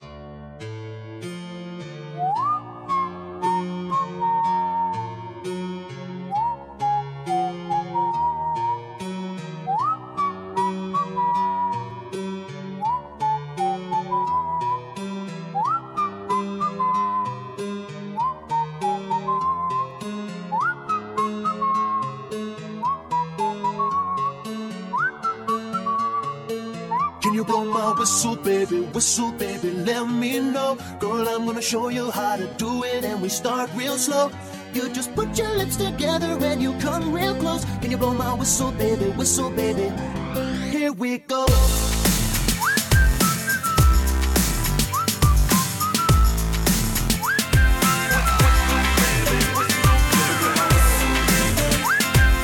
WhistleIntro.ogg